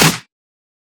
edm-clap-59.wav